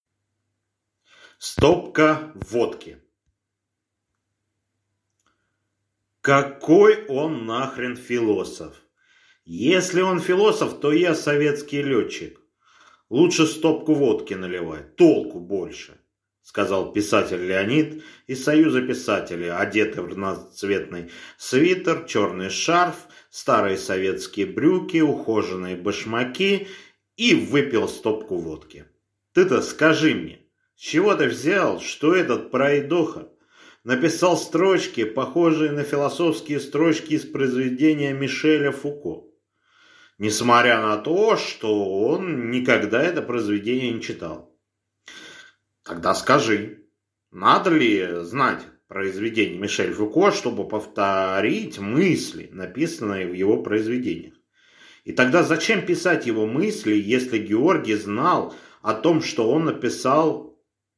Аудиокнига Стопка водки!